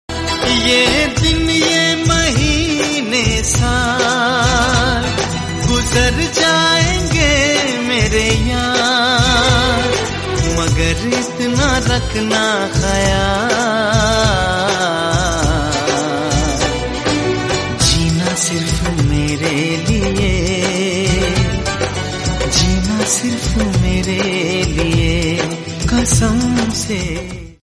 Love Songs
Bollywood 4K Romantic Song